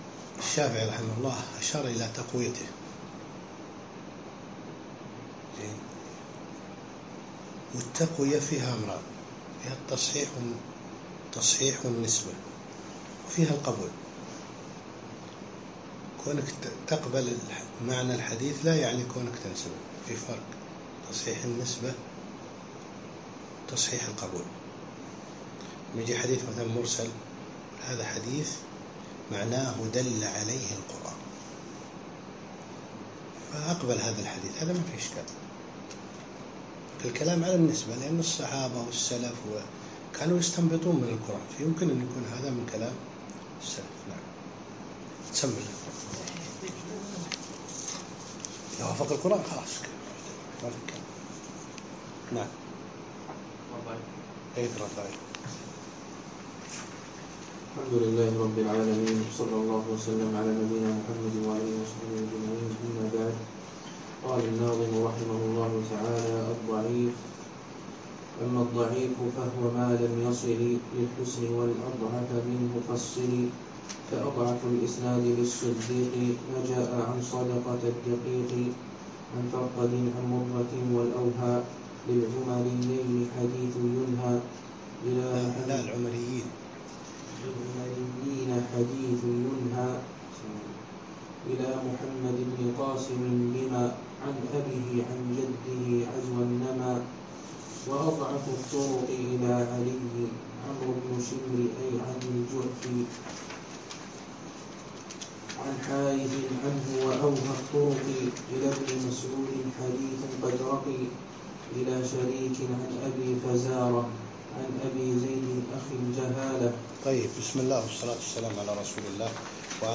الدرس الخامس